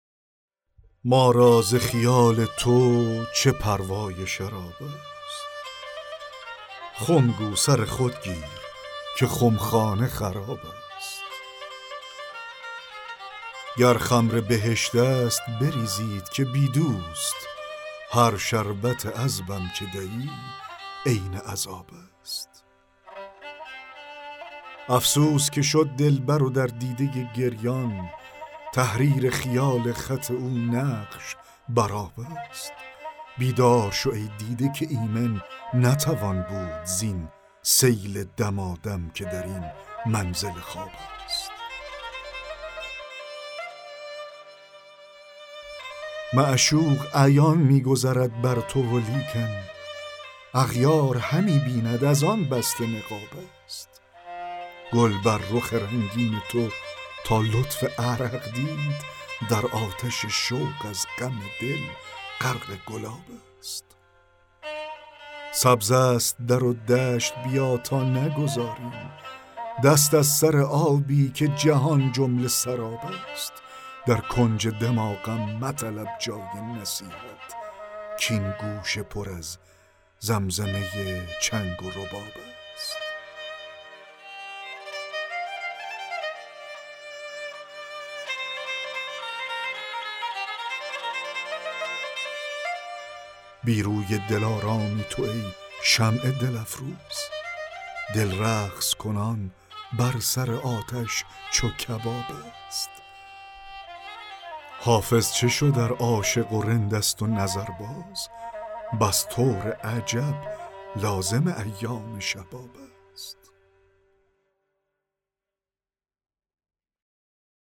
دکلمه غزل 29 حافظ
دکلمه-غزل-29-حافظ-ما-را-ز-خیال-تو-چه-پروای-شراب-است.mp3